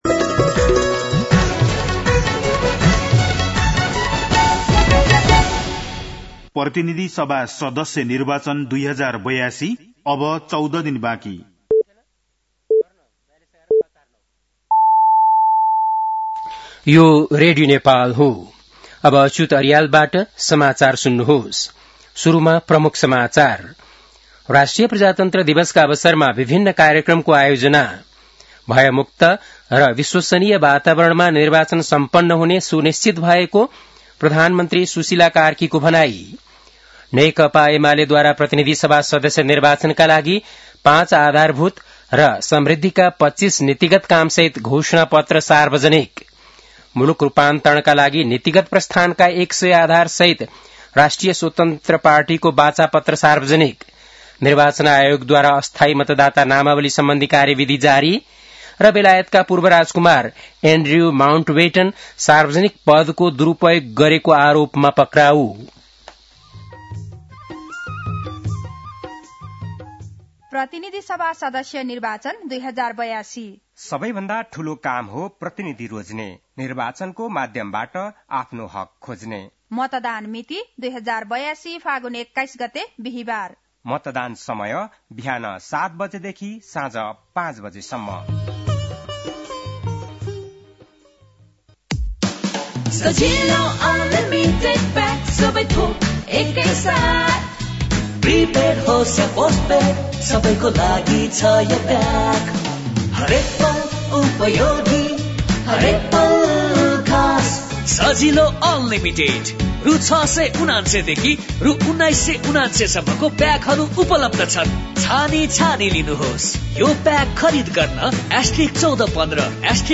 बेलुकी ७ बजेको नेपाली समाचार : ७ फागुन , २०८२
7.-pm-nepali-news-1-7.mp3